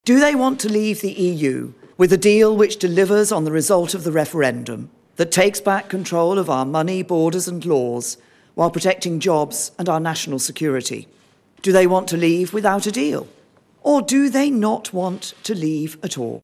Mrs. May says it’s now time for MPs to make their minds up……..